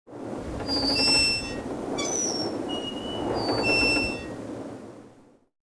Звуки ковбоев, дикого запада
• Качество: высокое
Знак скрипит на ветру